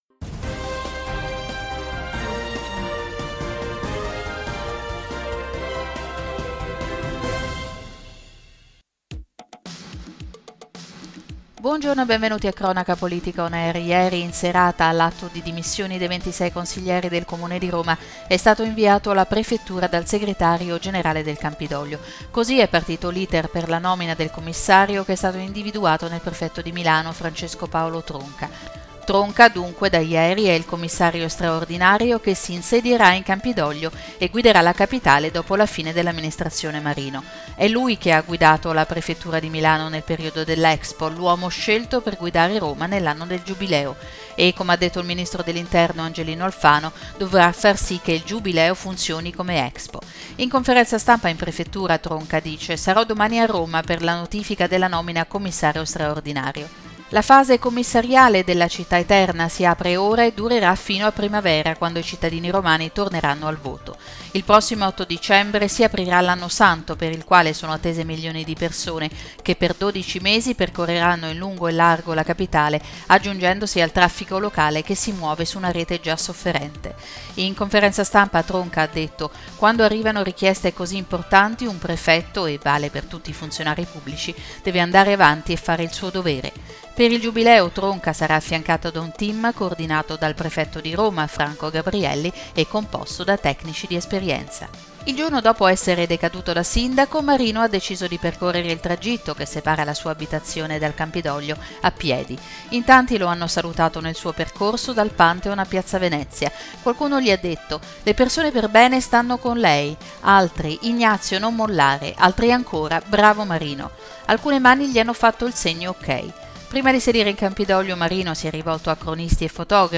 Notiziario 31/10/2015 - Cronaca politica